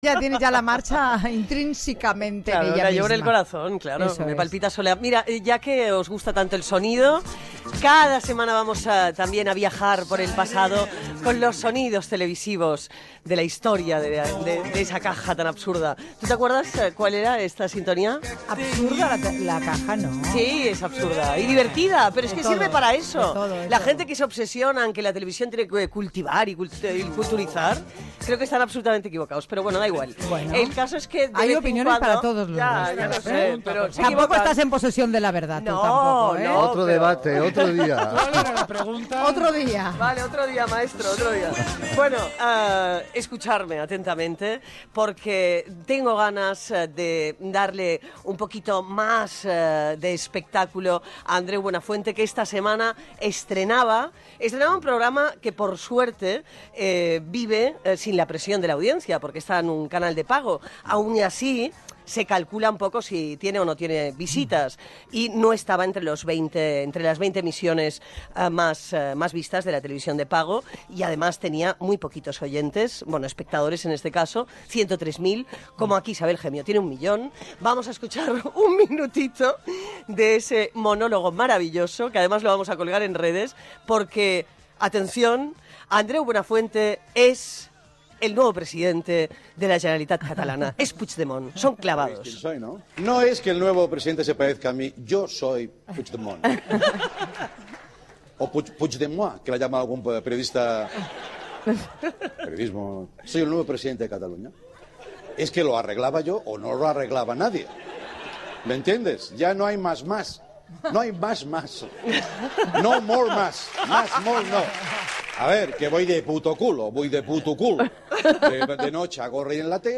Secció "La teleadicta": Andreu Buenafuente estrena "Late motiv" , fragment d'un monòleg seu sobre el president de la Generalitat Carles Puidemon, opinions sobre el "look" dels representants polítics de Podemos a la televisió (Pilar Cernuda, Salvador Sostres)
Entreteniment